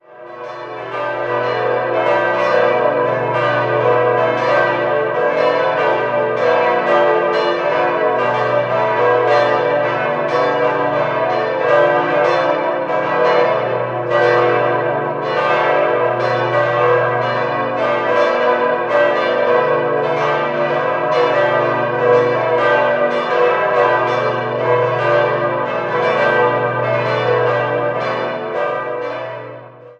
Die architektonisch interessante Pfarrkirche mit dem ovalen Grundriss und freistehendem Turm wurde im Jahr 1952 eingeweiht. 5-stimmiges Geläut: h°-d'-e'-fis'-a' Die Glocken wurden 1954 von Friedrich Wilhelm Schilling in Heidelberg gegossen.